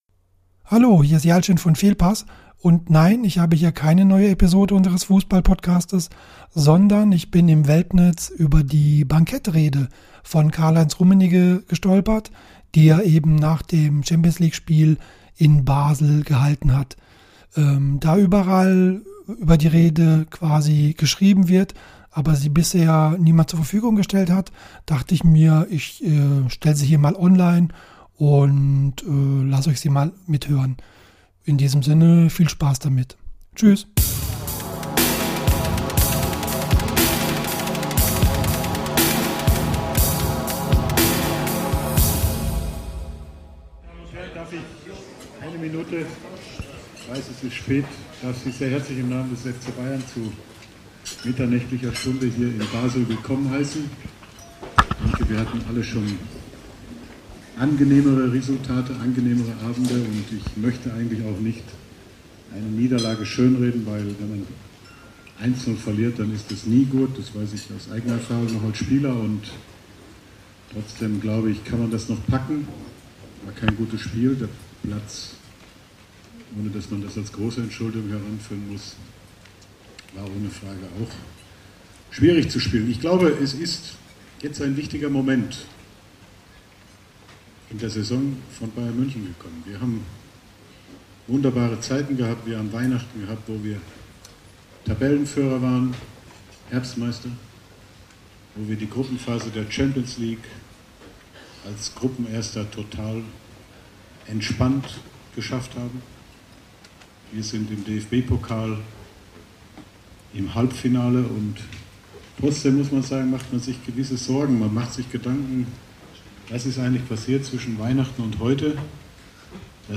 Bankettrede von Karl-Heinz Rummenigge nach der Champions-League-Pleite beim FC Basel
IMHO: Er war noch etwas zu nett, aber eindringlich und bestimmt.
fehlpass-Rummenigge-Bankettrede-Basel.mp3